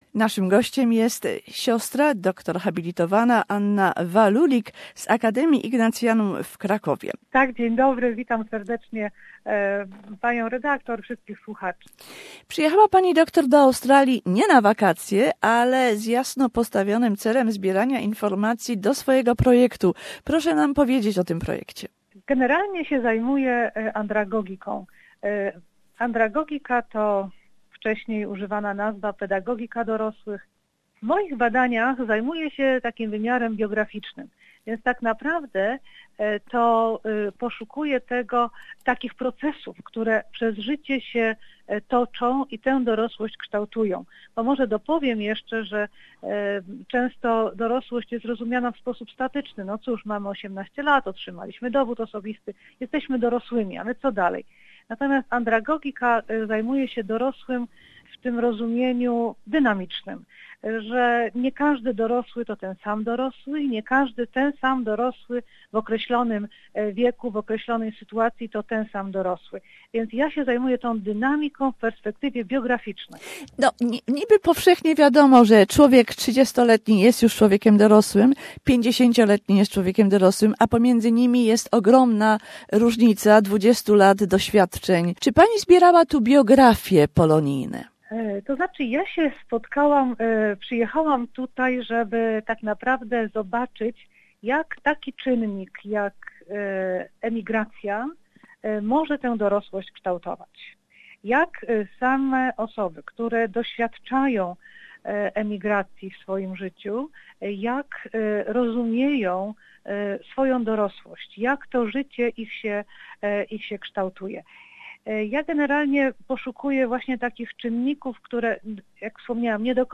A conversation with sociologist